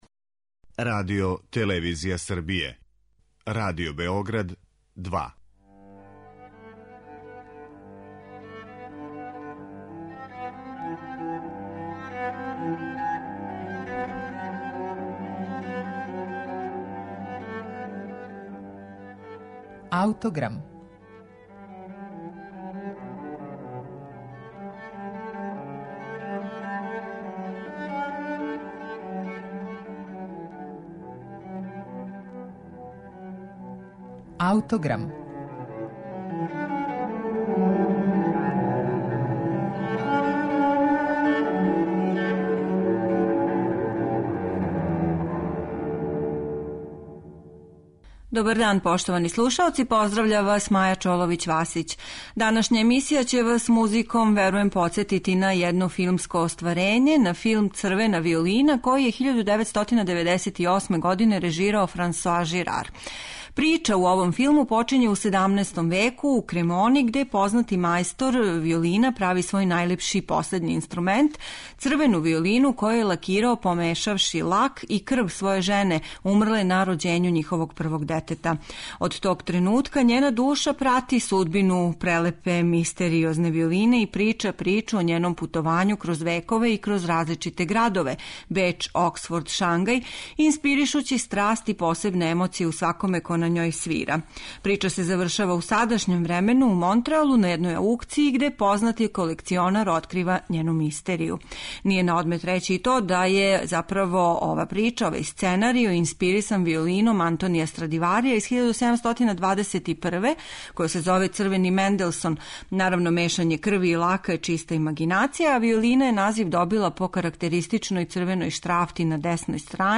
Више о овим партитурама и саму музику чућете у емисији